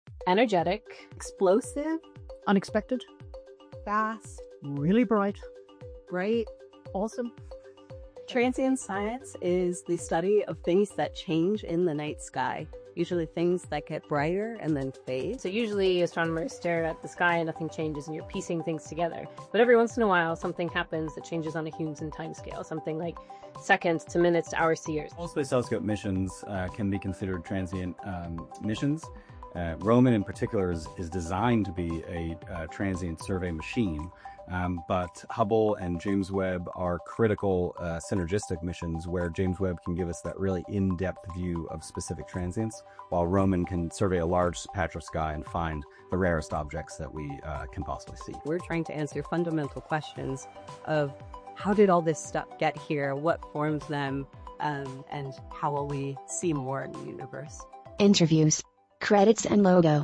• Audio Description
Audio Description.mp3